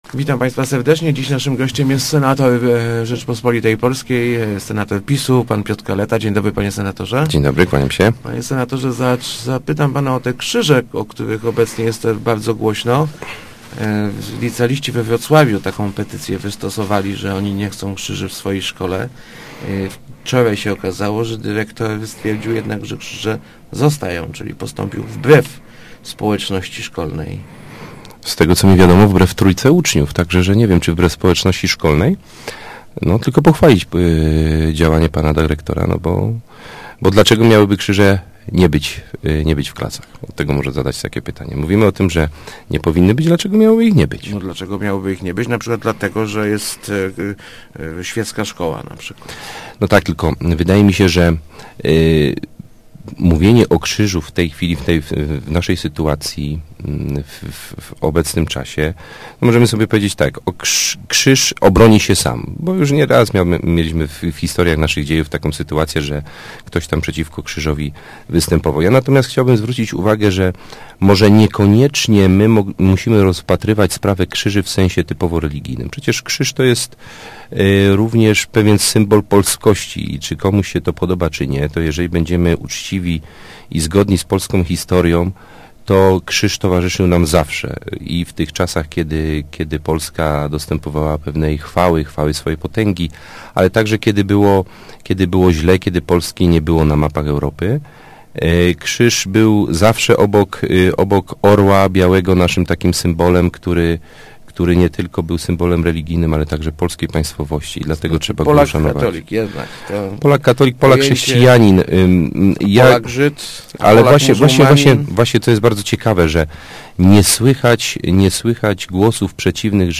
pkaleta80.jpgKrzyż jest symbolem naszej tożsamości narodowej, tak jak orzeł – mówił w Rozmowach Elki senator PiS Piotr Kaleta. Jego zdaniem ten symbol chrześcijaństwa nie przeszkadza innym wyznaniom religijnym, a wyrok trybunału w Strasburgu raczej zaognia, niż porządkuje sytuację.